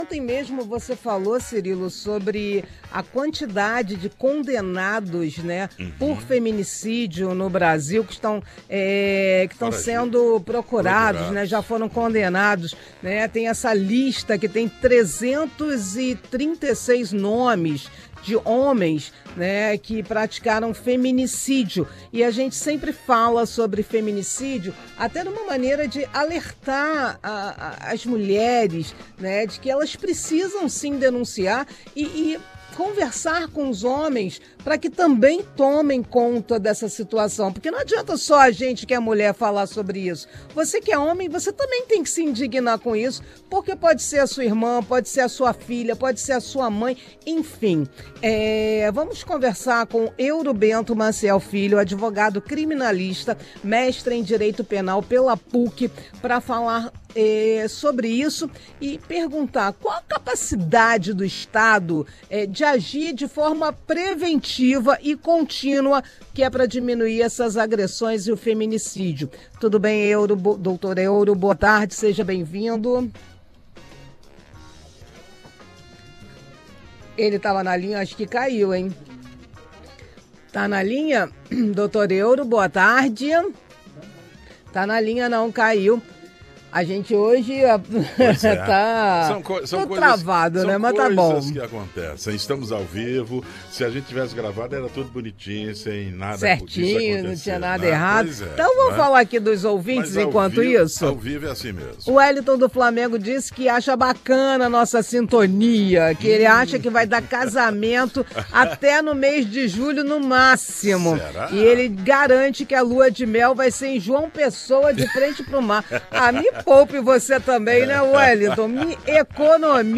Rádio Nacional | Feminicídio